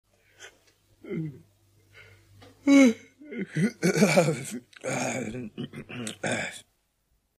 Звуки зевоты
Мужчина забавно зевает и подкашливает